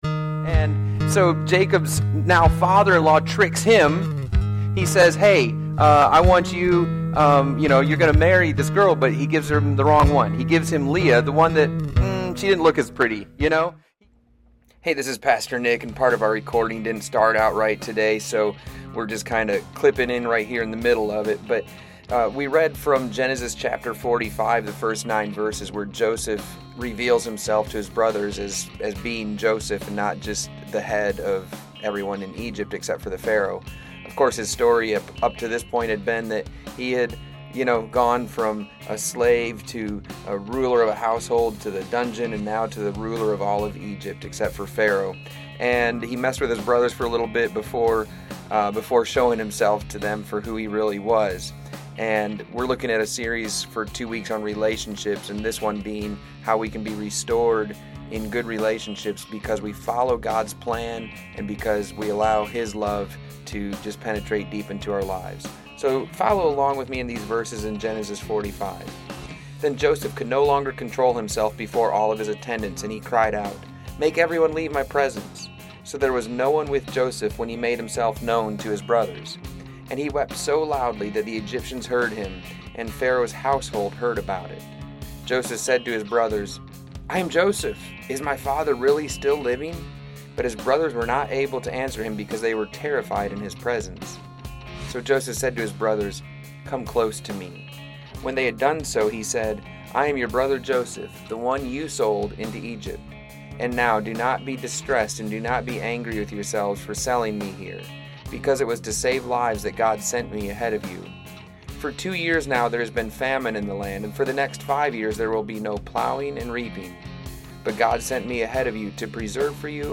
The sermon parallels Joseph's family drama with our universal need for healing and restoration in relationships.